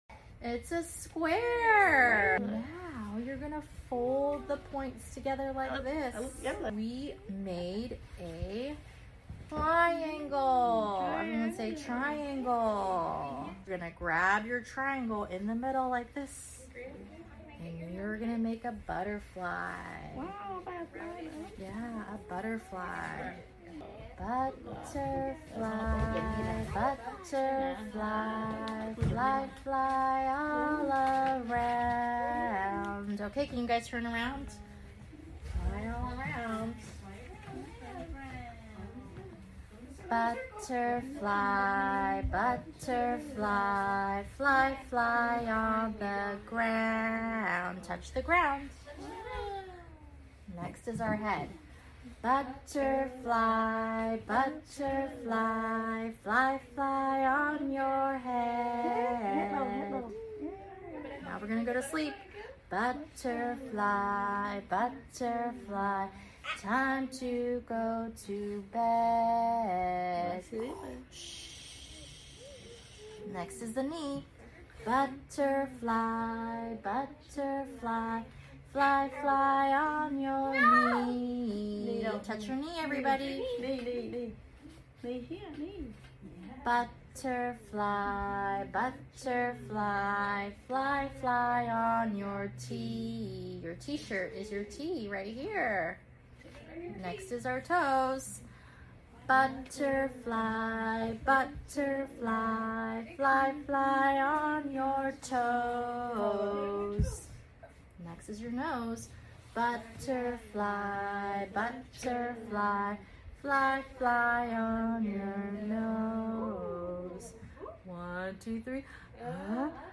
butterfly song